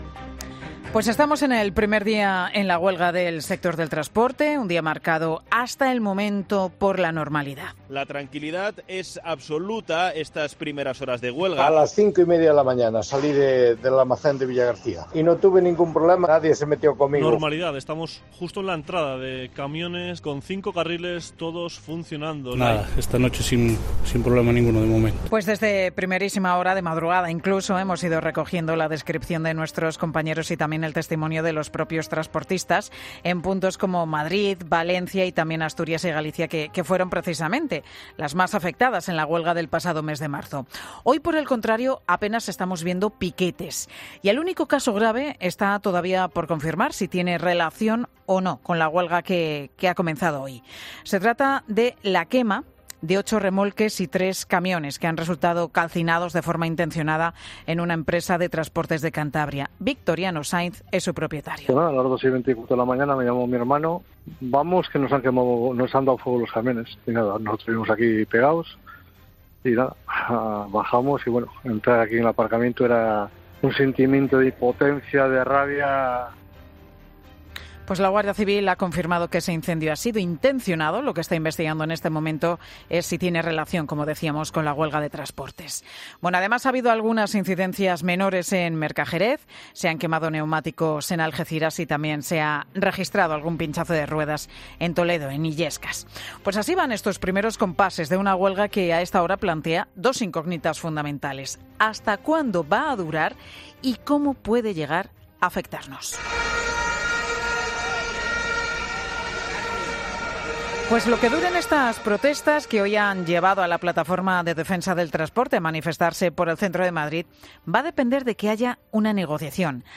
Los transportistas no se moverán del Ministerio de Raquel Sánchez hasta que les reciba. Crónica